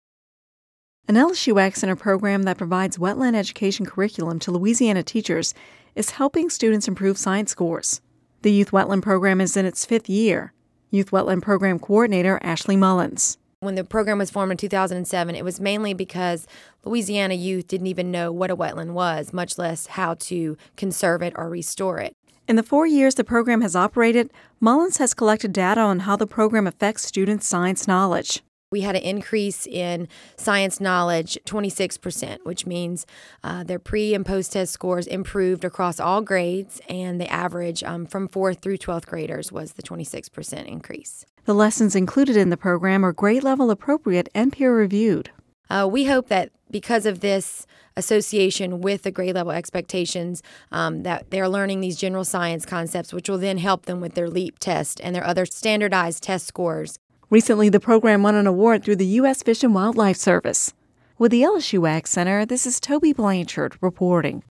(Radio News 04/04/11) An LSU AgCenter program that provides a wetland education curriculum to Louisiana teachers each year is helping students improve science scores. The Youth Wetland Program is in its fifth year.